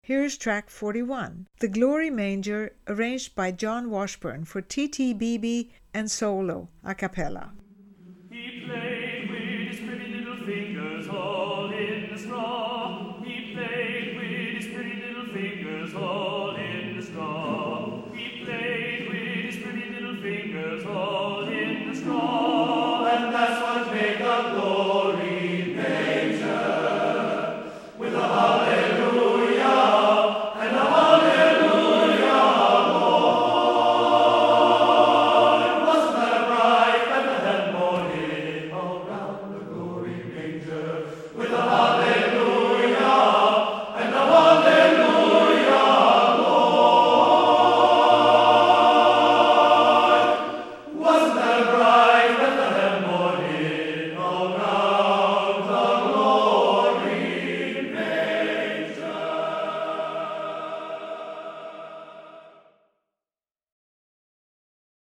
Composer: Spirituals
Voicing: TTBB a cappella